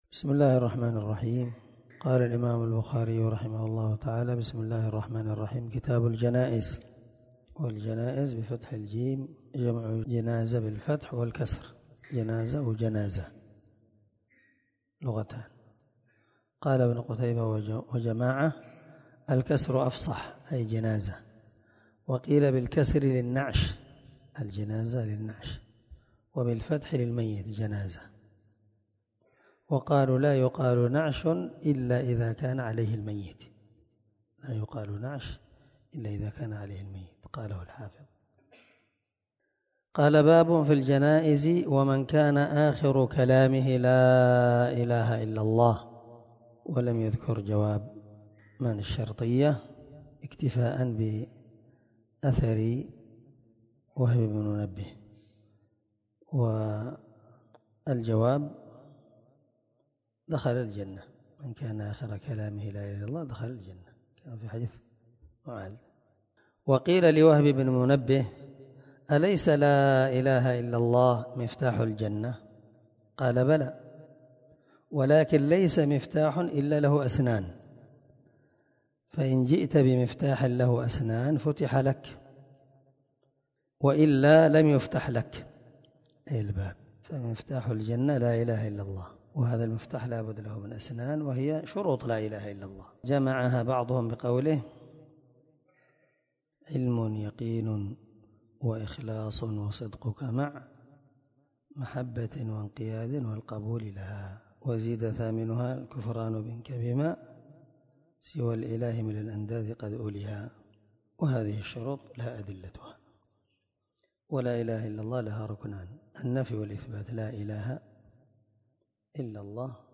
728الدرس 1من شرح كتاب الجنائز حديث رقم(1237-1238 )من صحيح البخاري